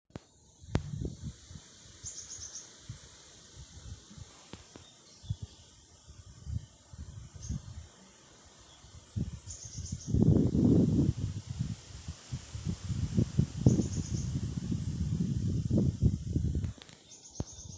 Great Tit, Parus major
Administratīvā teritorijaTalsu novads
StatusSinging male in breeding season